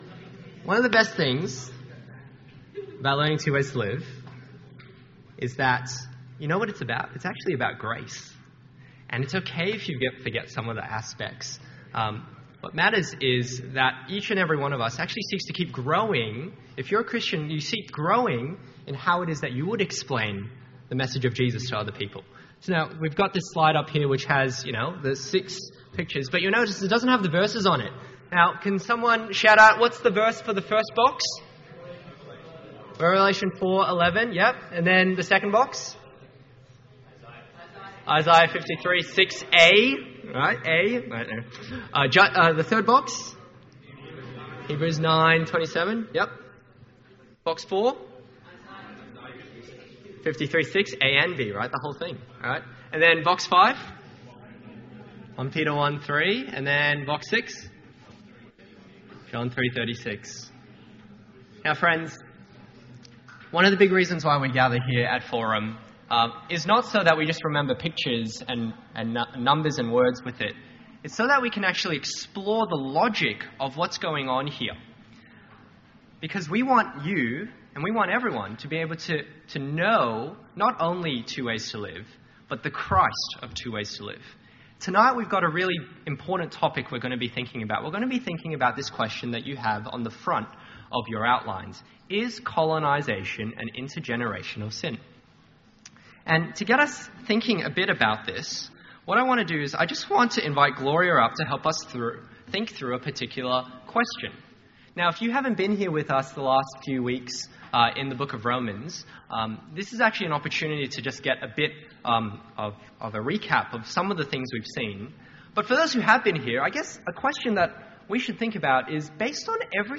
INCOMPLETE RECORDING – only first half available
An edited audio version of Week 2, Term 2 of Forums 2024. Forum is a 2 hour session of interactive Bible teaching for 18-30 year olds, and this week was an ANZAC Day special Forum.